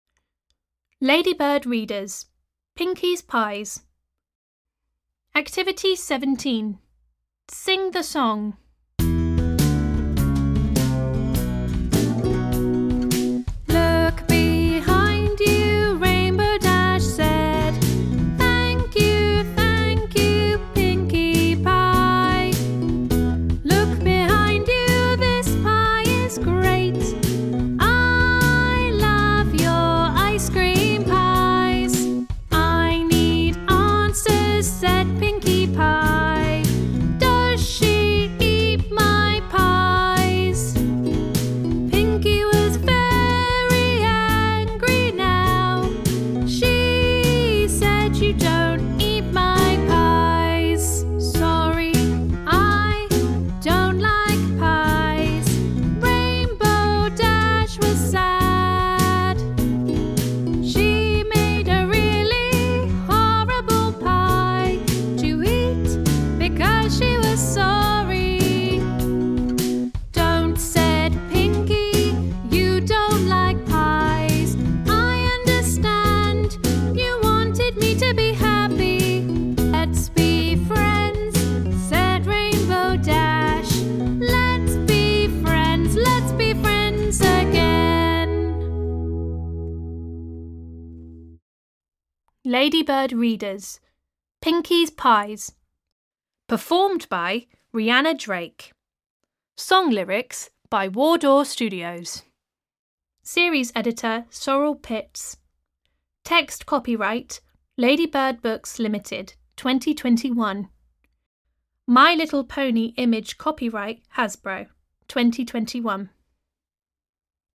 Audio UK
Song